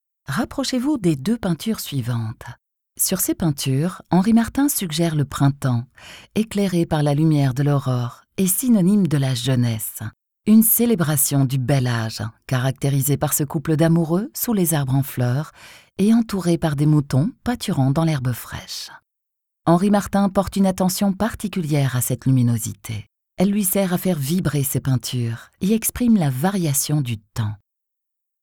Warm, Diep, Vertrouwd, Volwassen, Zakelijk
Audiogids